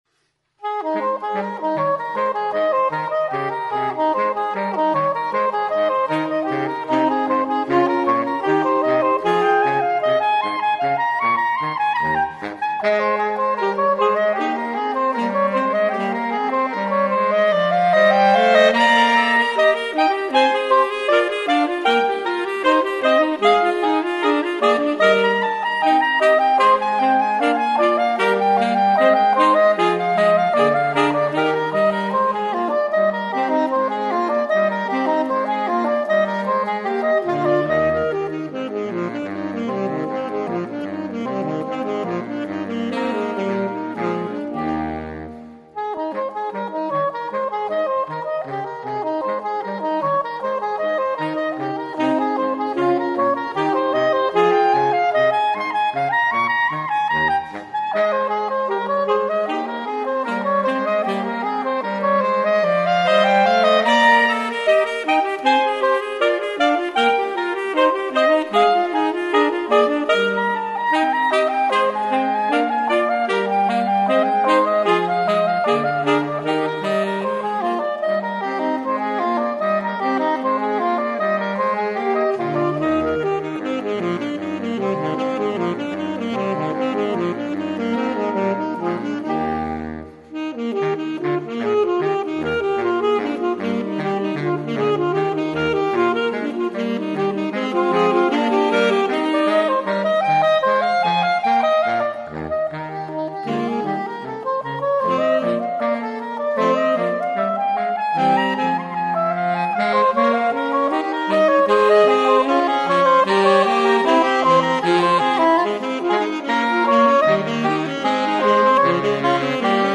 per quartetto di sax